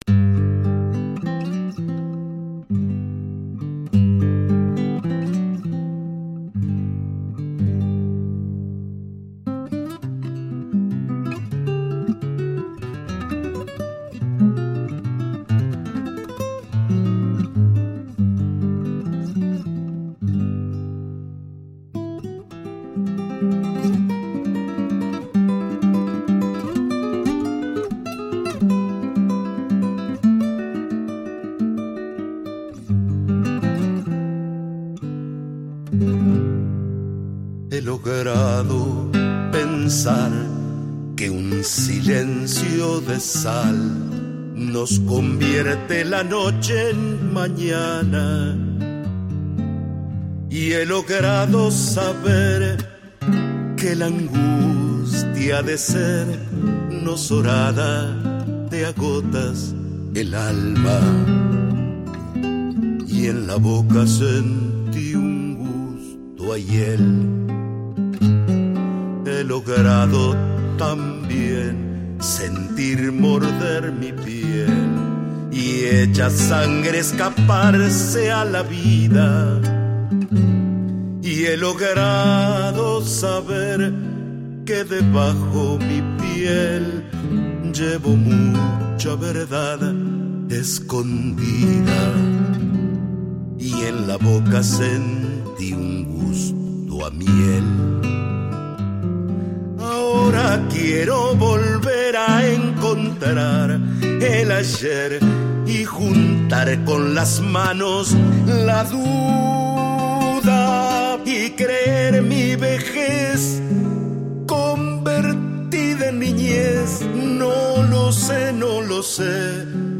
Zamba